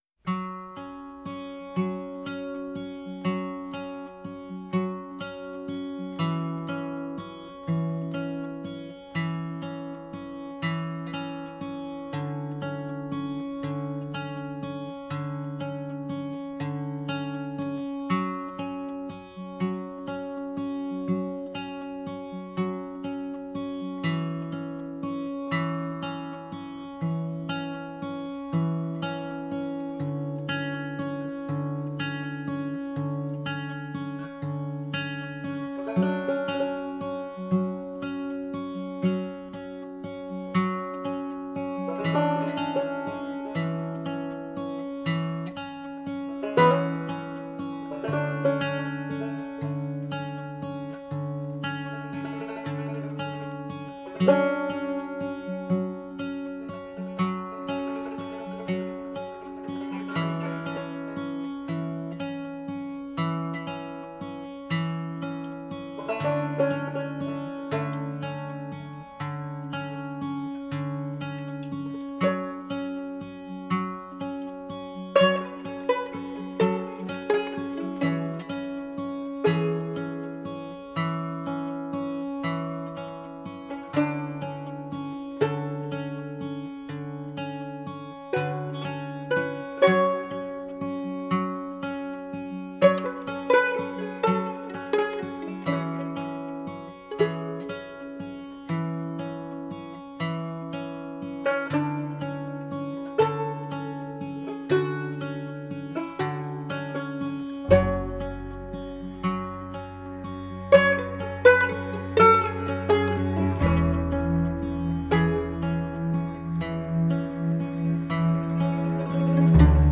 موسیقی سبک Post Rock